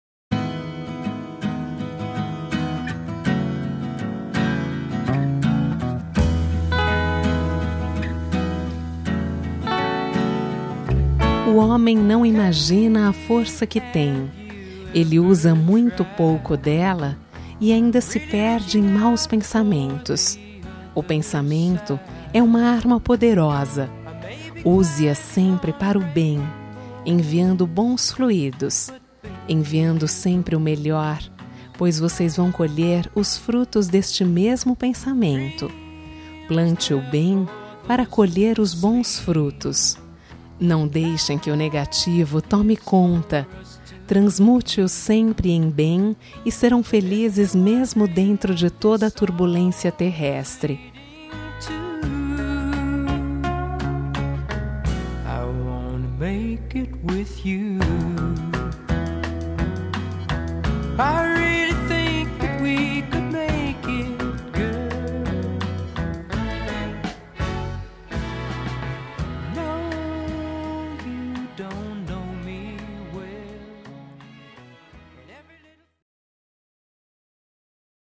Telemensagem de Otimismo – Voz Feminina – Cód: 100103